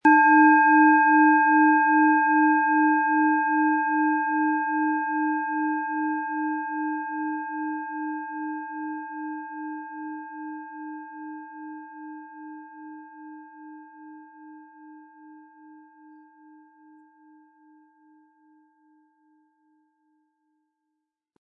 Planetenton 1
Handgearbeitete tibetanische Planetenschale Eros.
Besonders schöne Töne zaubern Sie aus der Eros Klangschale, wenn Sie sie sanft mit dem beiliegenden Klöppel anspielen.
SchalenformBihar
MaterialBronze